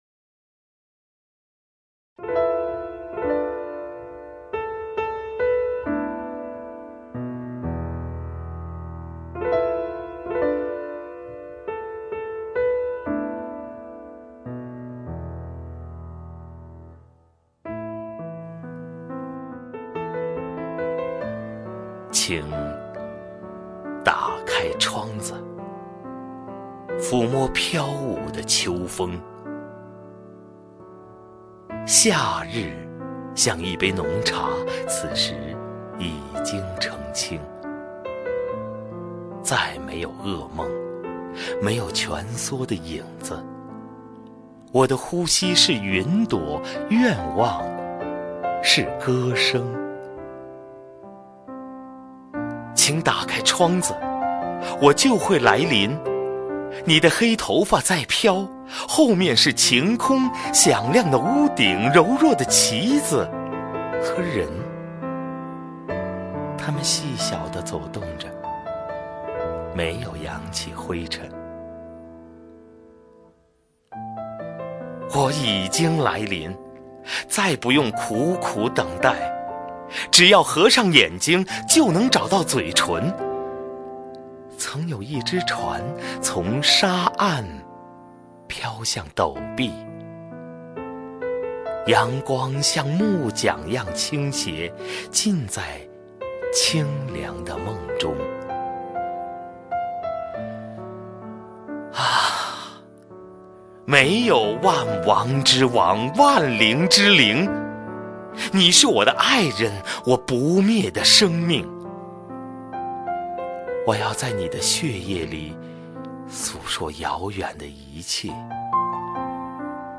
首页 视听 名家朗诵欣赏 赵屹鸥
赵屹鸥朗诵：《来临》(顾城)